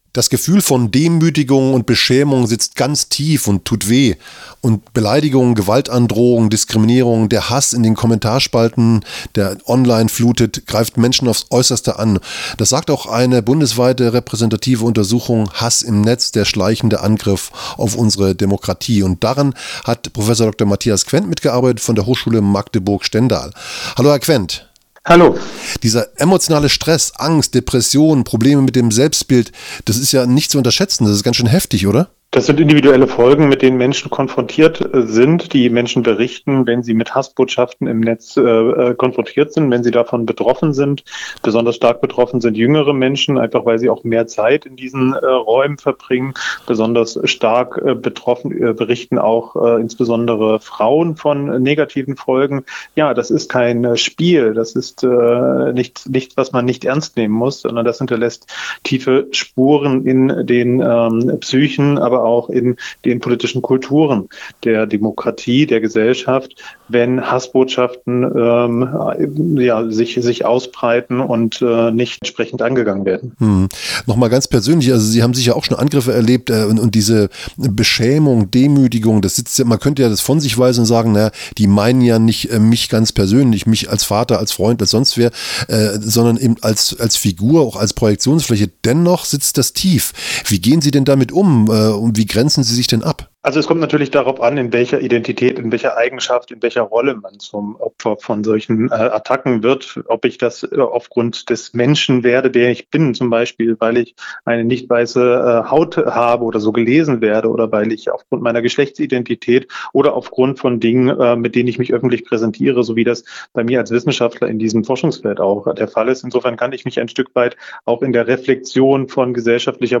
Das Gespr�ch